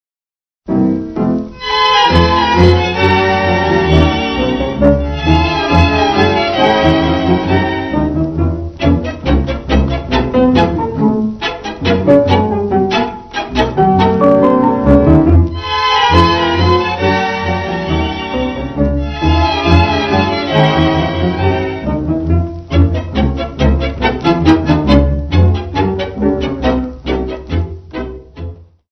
Klassische Tangos